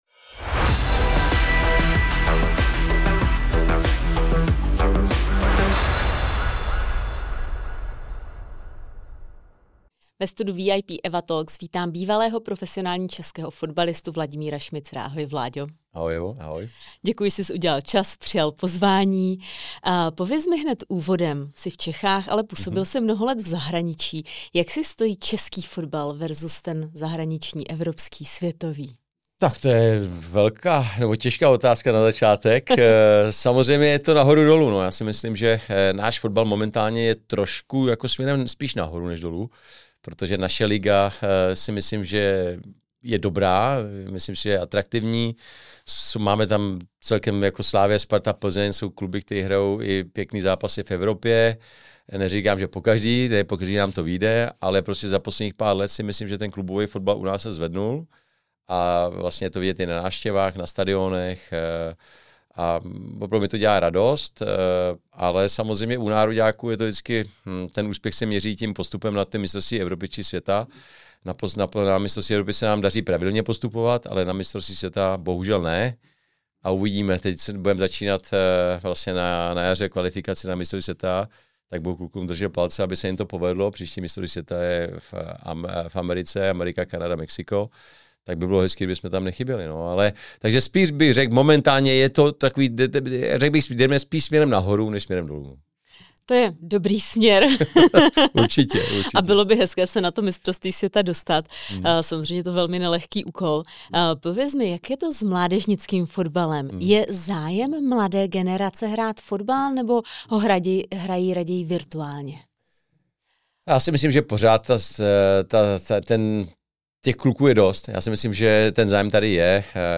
Tentokrát pozvání přijal bývalý fotbalista a podnikatel Vladimír Šmicer